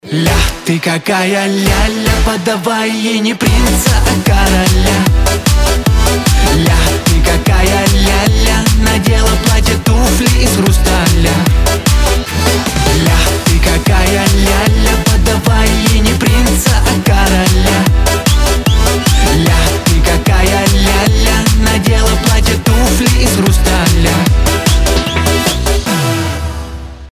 • Качество: 320, Stereo
позитивные
веселые